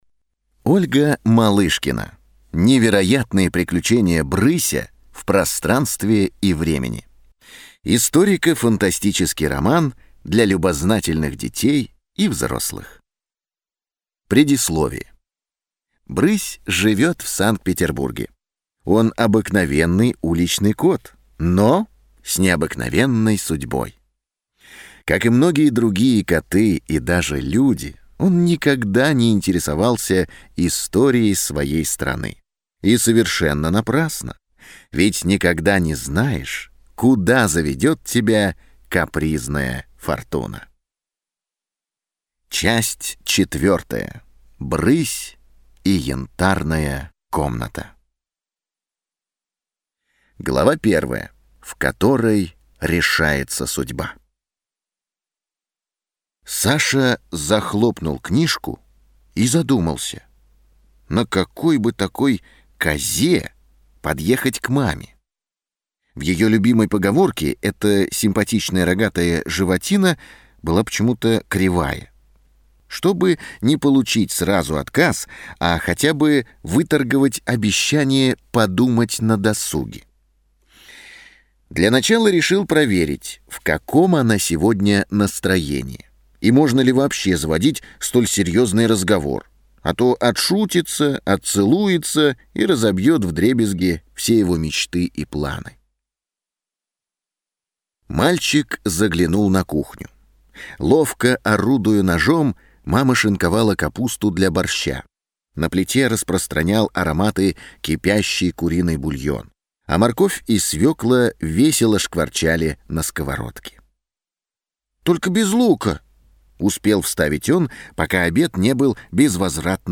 Аудиокнига Книга 4. Брысь и Янтарная комната | Библиотека аудиокниг